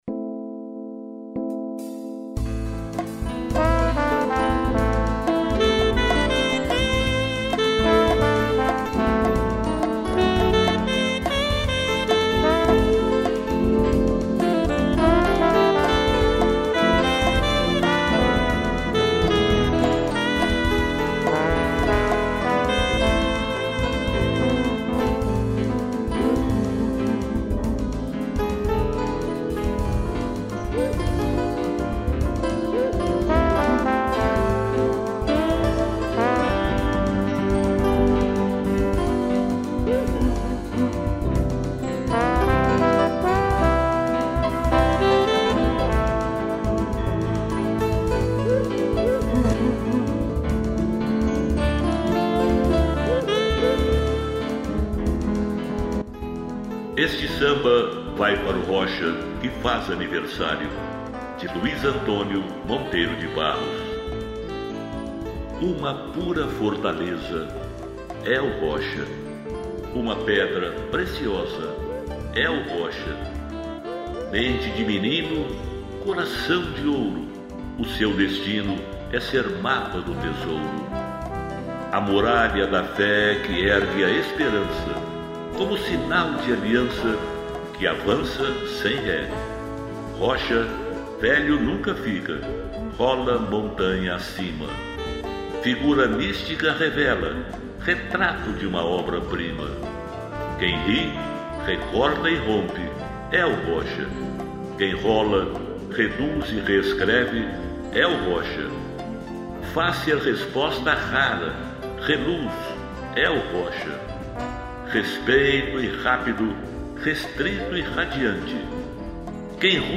Poemas de vários poetas interpretados
piano, sax e trombone